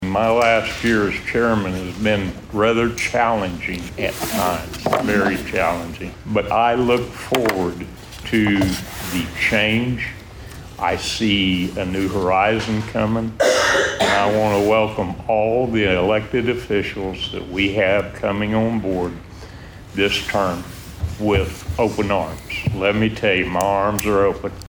District 3 Commissioner Charlie Cartwright was re-appointed as chairman and District 1 Commissioner Anthony Hudson will serve as vice-chairman.